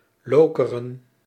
Lokeren (Dutch pronunciation: [ˈloːkərə(n)]
Nl-Lokeren.ogg.mp3